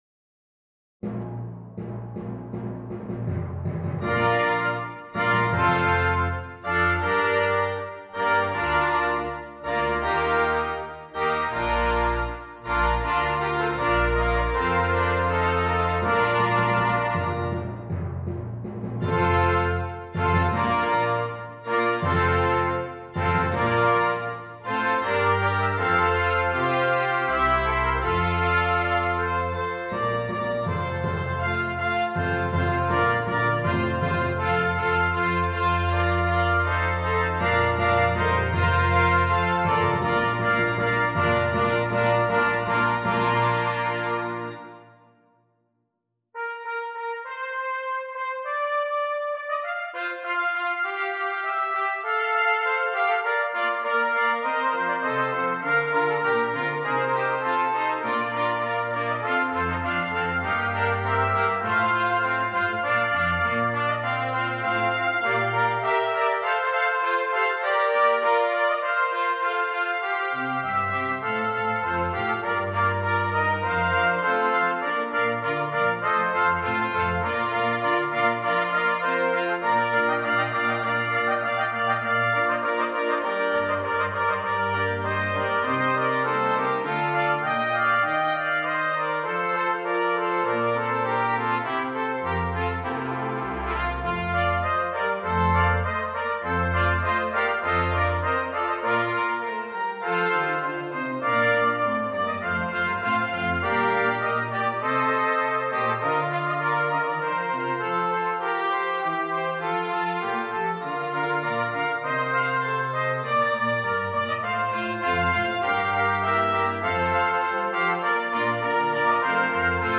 4 Trumpets, Organ and Timpani